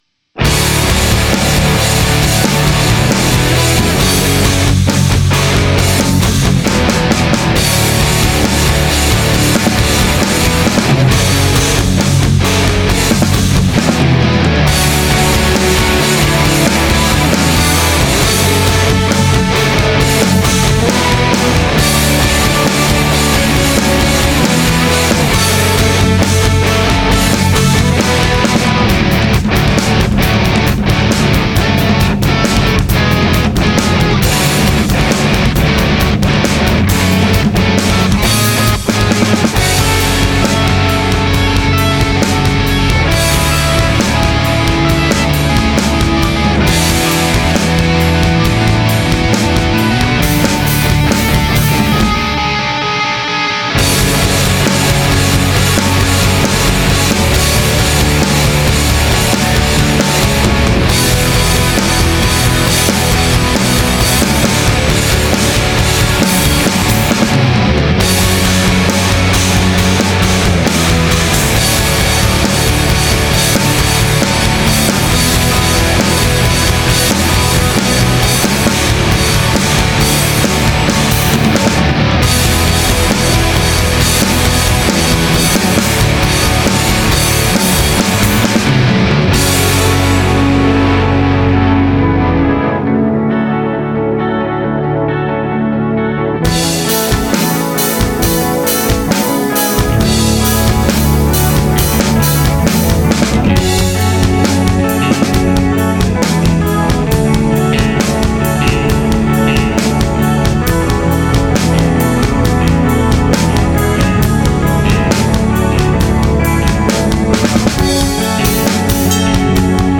The song was recorded in Ardour 8.4.0 from Trisquel GNU/Linux-libre, with…